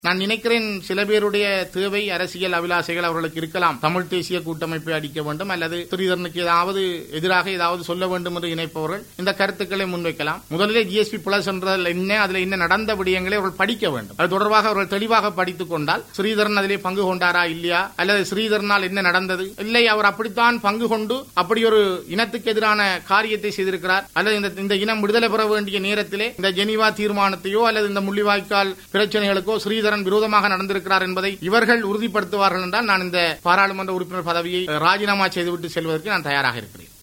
யாழ்ப்பாணத்தில் இன்று இடம்பெற்ற செய்தியாளர் சந்திப்பில் கலந்து கொண்டு உரையாற்றிய போதே அவர் இதனை குறிப்பிட்டுள்ளார்.